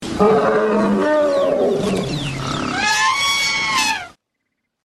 Звук Угрюмого Мамонта